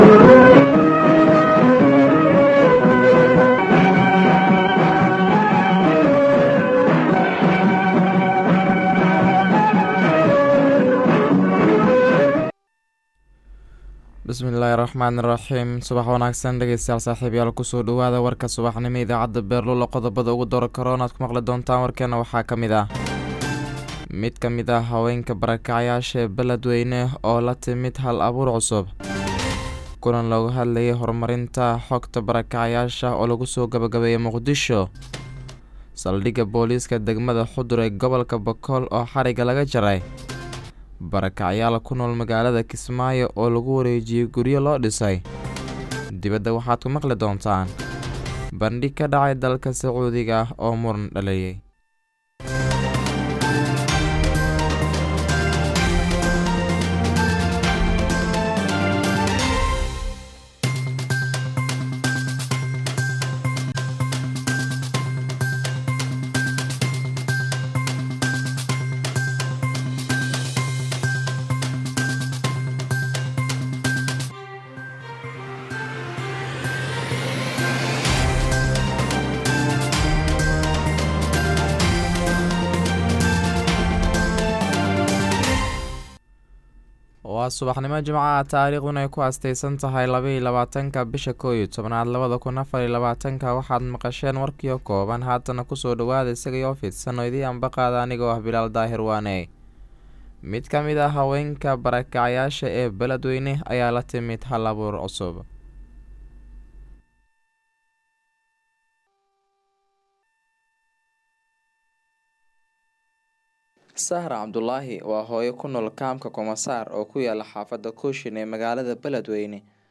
Warka Subaxnimo ee Radio Beerlula